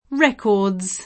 record [r$kord; ingl. r$kood] s. m.; inv. (pl. ingl. records [